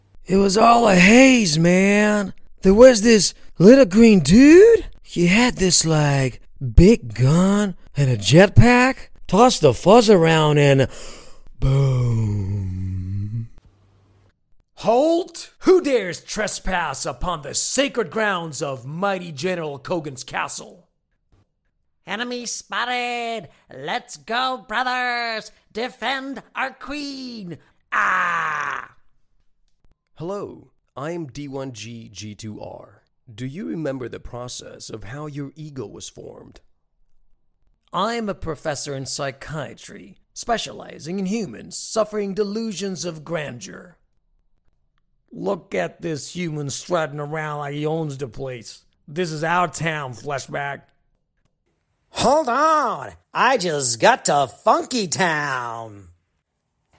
Accent: American & Greek Characteristics: Reassuring and Engaging Age: 30-40 Commercial & Corporate Gaming Narrative Greek